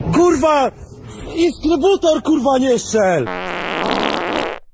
Instrybutor fart
instrybutor-fart.mp3